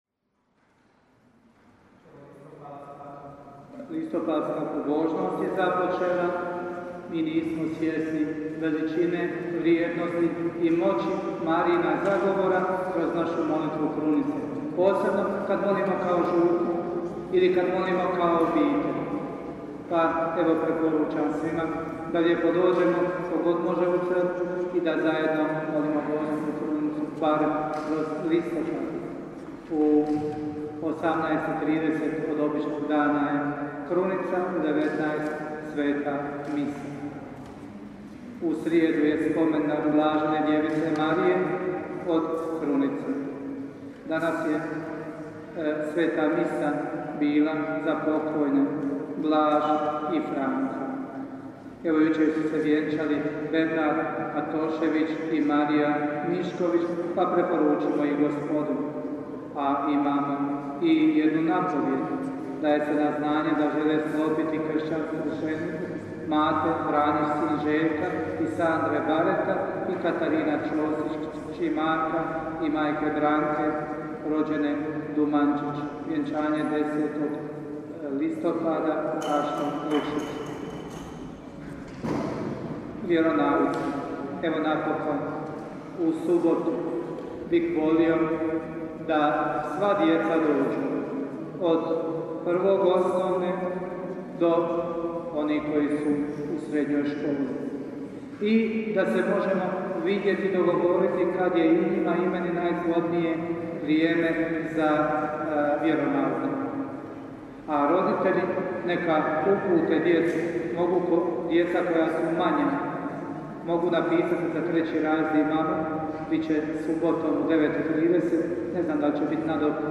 župne obavjesti (oglasi i napovjedi):